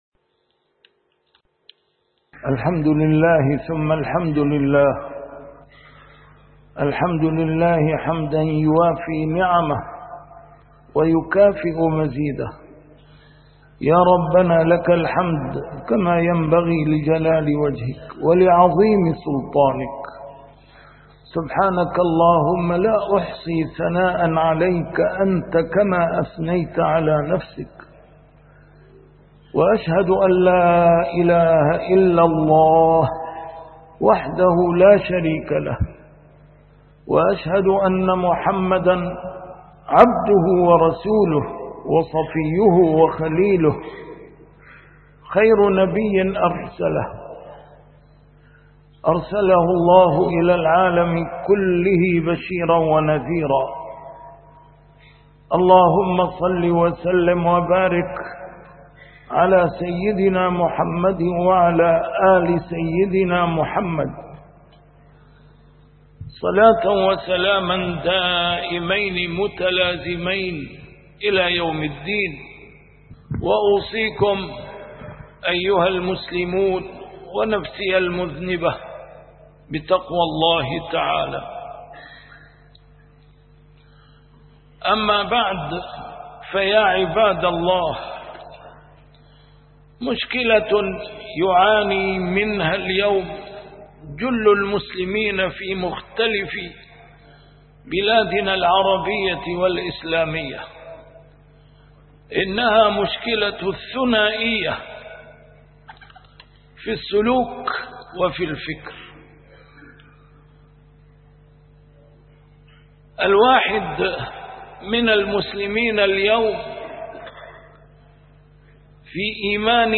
نسيم الشام › A MARTYR SCHOLAR: IMAM MUHAMMAD SAEED RAMADAN AL-BOUTI - الخطب - الثنائية مشكلة العصر...تشخيصها وعلاجها